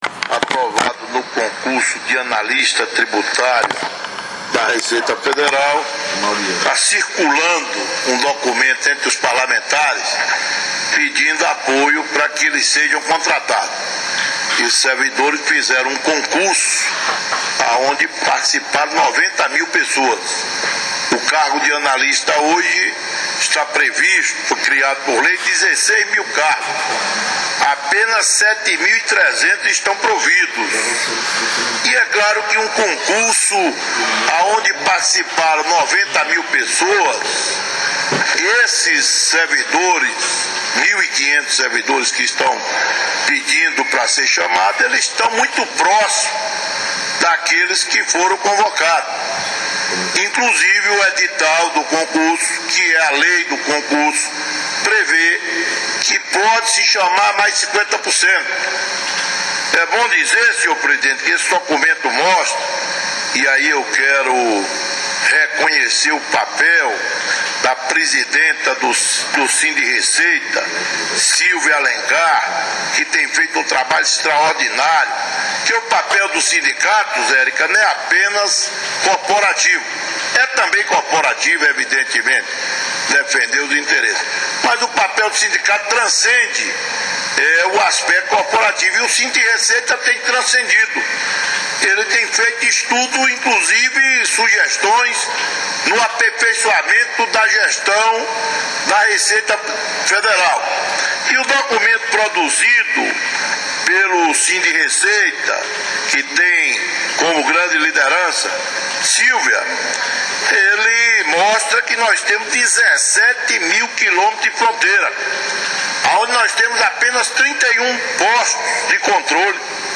O deputado federal Amauri Teixeira (PT/BA) fez, na manhã de sexta-feira, dia 22, na Câmara dos Deputados, um discurso defendendo a convocação do grupo de excedentes do último concurso para Analista-Tributário da Receita Federal. O deputado conclamou os parlamentares no Congresso Nacional para que assinem a petição encaminhada pelo Grupo de Analistas-Tributários que solicita a convocação dos excedentes do último concurso dentro dos 50% permitidos pelo Edital.
discurso-deputado-Amauri-Teixeira.mp3